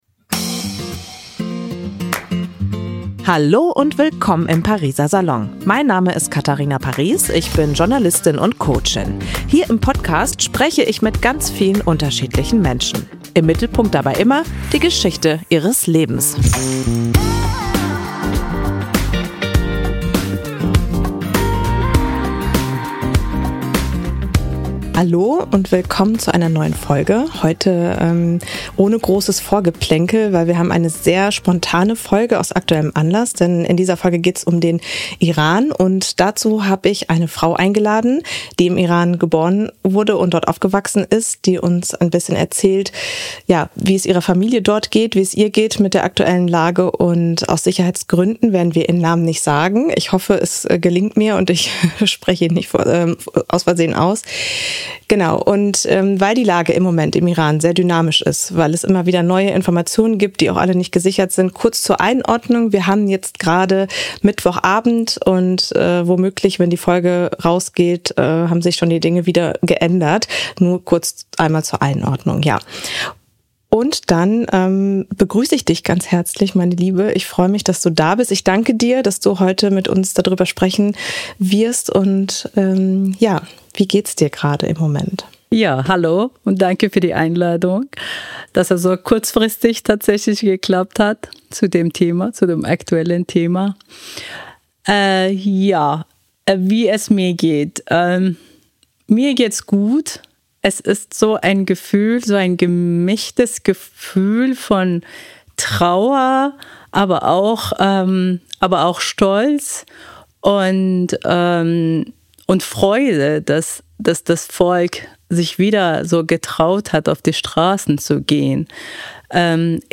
Sehr offen und ehrlich erzählt sie, wie es war, im Iran aufzuwachsen. Sie spricht über die aktuellen Proteste und darüber, was sie sich von europäischen und anderen Regierungen wünscht. Abseits der Aktualität erfährt man in diesem Gespräch aber auch viel über die Schönheit des Landes und die Herzlichkeit der Menschen dort.